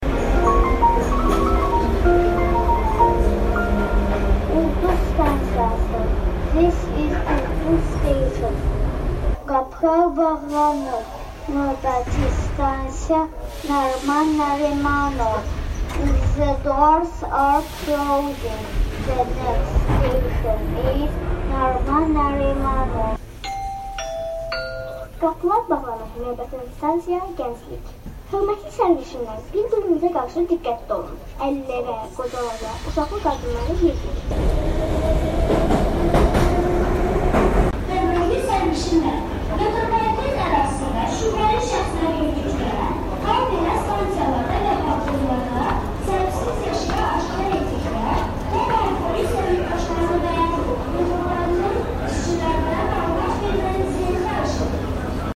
Belə ki, Bakı metropolitenində qatarlarda və stansiyalarda bütün elanları uşaqlar oxuyub.
Metroda uşaqlar elan oxuyur